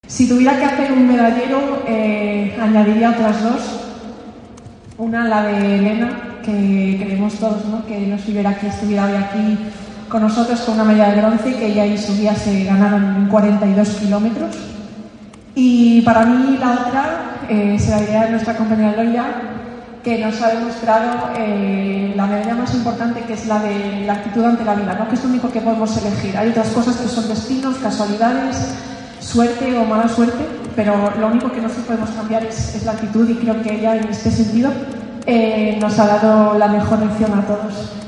Foto de familia del acto de acogida en Madrid del equipo paralímpicoLa ministra de Educación, Formación Profesional y Deportes, Pilar Alegría, junto al ministro de Derechos Sociales, Consumo y Agenda 2030, Pablo Bustinduy, homenajearon el 10 de seprtiembre en Madrid al Equipo Paralímpico Español, tras el éxito logrado en los Juegos de París 2024 en los que ha logrado un total de 40 medallas.
En nombre de los deportistas tomó la palabra la doble campeona paralímpica de triatlón, Susana Rodríguez, dijo emocionada que la actuación del equipo “ha sido brillante”.